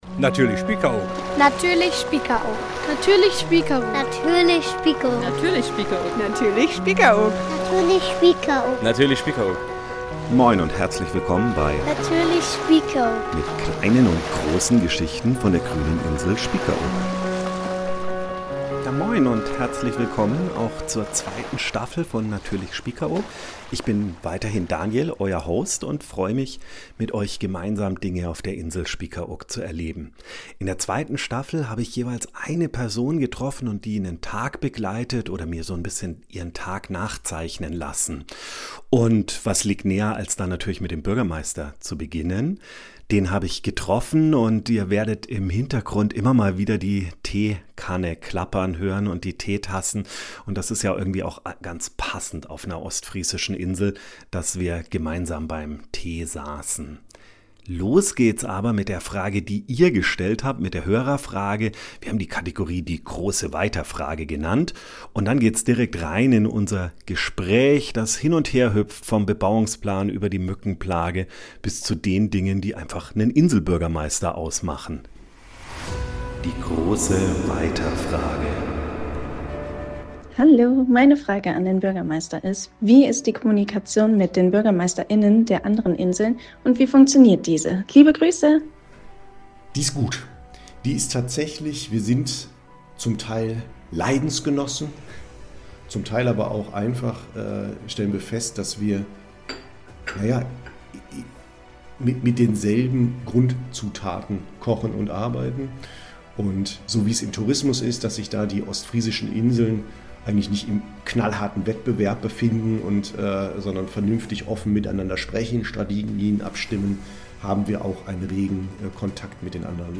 In dieser Staffel begleiten wir spannende Inselpersönlichkeiten durch ihren (Arbeits-)Alltag – und starten direkt mit einem ganz besonderen Gast: Inselbürgermeister Patrick Kösters. Bei einer gemütlichen Tasse Tee sprechen wir mit ihm über das Leben und Arbeiten auf Spiekeroog, seine tägliche Routine und was ihm besonders am Herzen liegt.